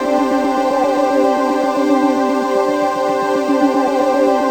DM PAD2-80.wav